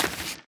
till2.ogg